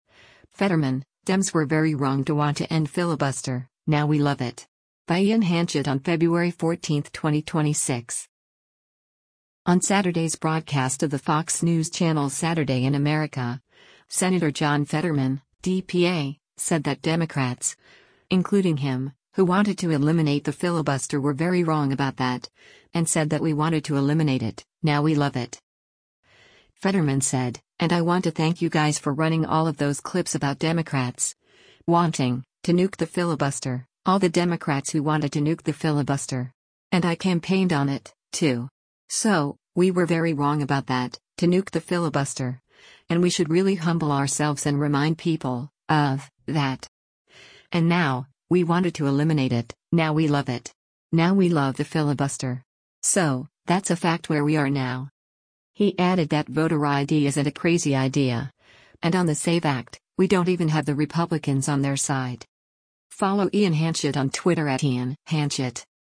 On Saturday’s broadcast of the Fox News Channel’s “Saturday in America,” Sen. John Fetterman (D-PA) said that Democrats, including him, who wanted to eliminate the filibuster “were very wrong about that,” and said that “we wanted to eliminate it, now we love it.”